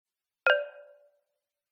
9 text send.mp3